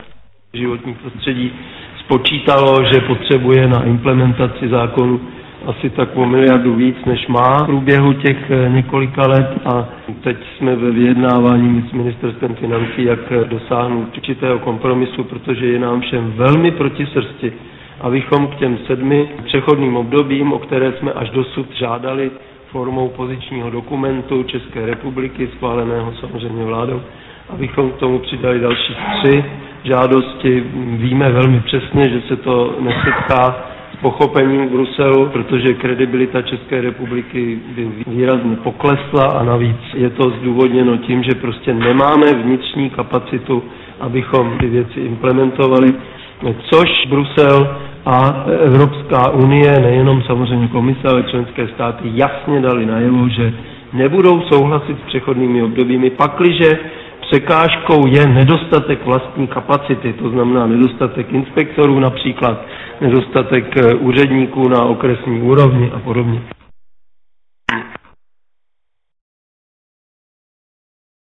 Na semináři věnovaném stavu životního prostředí v České republice ředitel Centra pro otázky životního prostředí Univerzity Karlovy, prof. Bedřich Moldan řekl, že dnes je jednání o životním prostředí v České republice daleko složitější než před deseti lety, a to nejen pokud jde o lobistické skupiny, zástupce různých průmyslových odvětví, odborové svazy a ekologické organizace, ale i v rámci jednání vlády a v rámci Ministerstva životního prostředí.